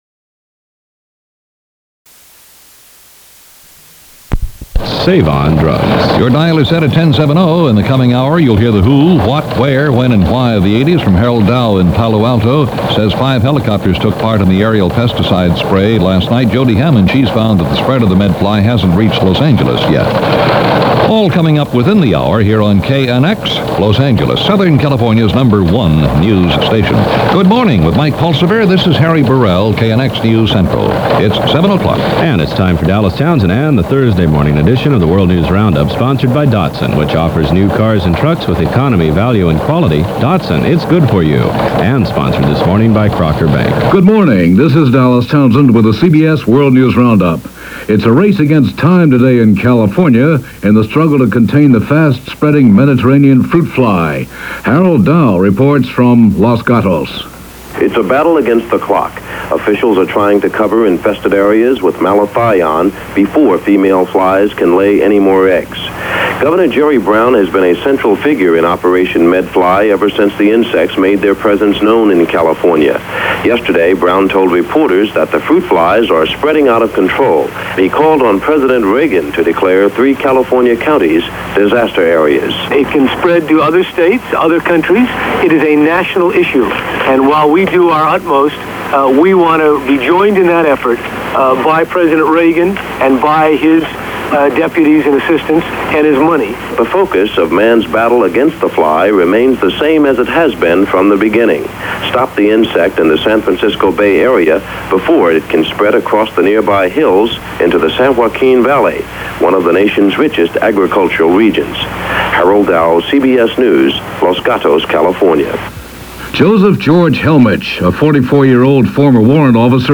Mediterranean Fruit Fly: The War Rages - Nabbing A Spy - Non-Proliferation - July 16, 1981 - CBS World News Roundup.